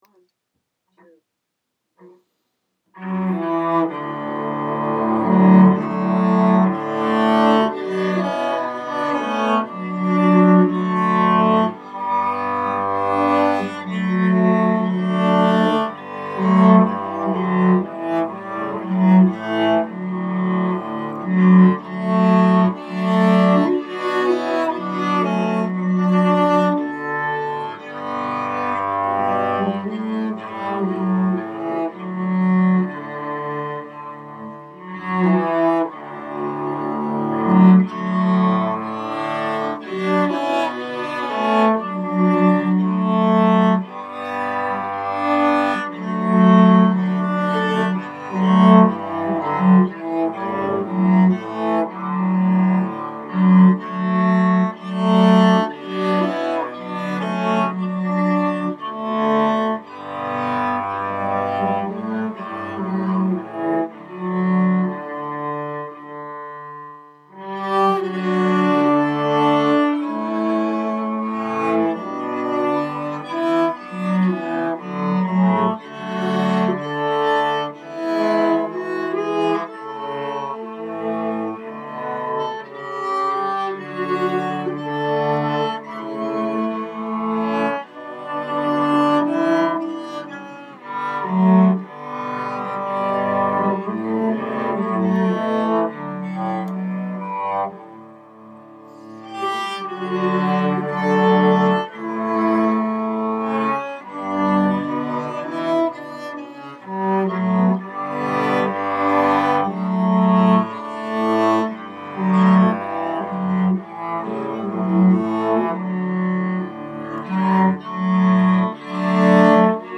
I couldn’t figure out how to mute my vocal count in, I’m pretty sure my levels are all messed up, and it’s possible that recording with the mic that’s built into your computer is not the best option, BUT! I don’t care.
This would be what five of me would sound like in a room…..
… and I ALMOST played it in tune! (almost.)
The song is a Scottish Tune, “Da Slockit Light,” meaning “The Extinguished Light.”
Da-Slockit-Light-5-Part.mp3